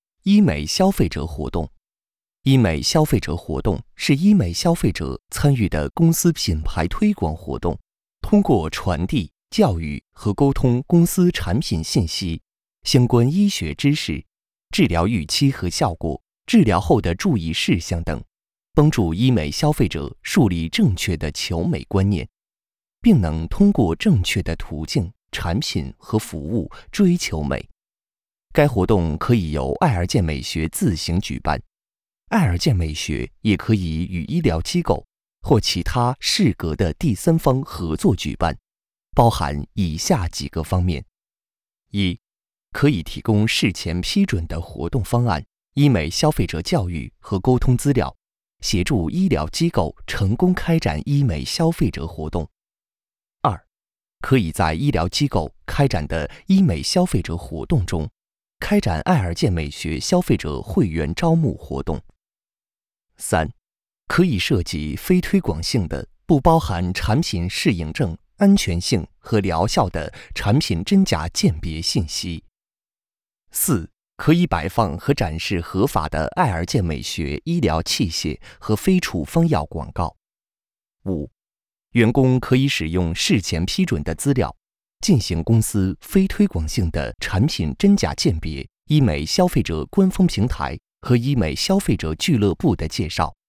Chinese_Male_005VoiceArtist_20Hours_High_Quality_Voice_Dataset